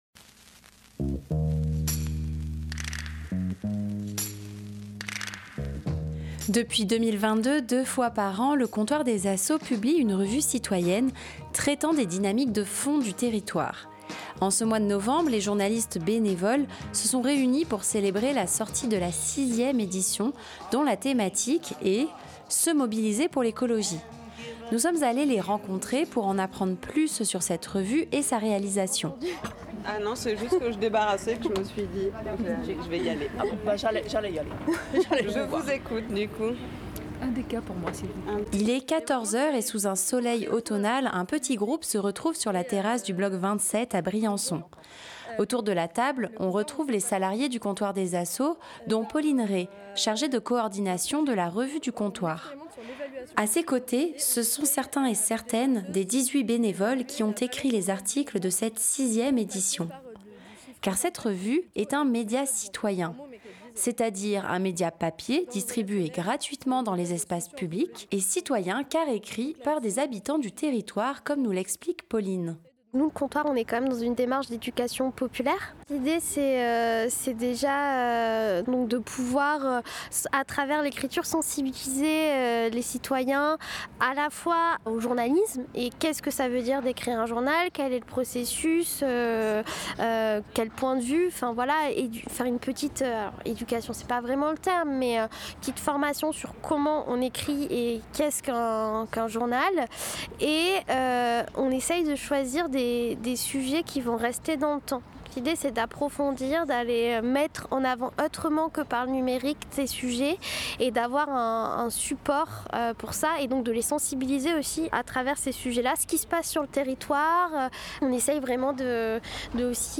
En ce mois de novembre, les journalistes bénévoles se sont réunies pour célébrer la sortie de la 6e édition dont la thématique est : se mobiliser pour l'écologie. Nous sommes allés les rencontrer pour en apprendre plus sur cette revue et sa réalisation.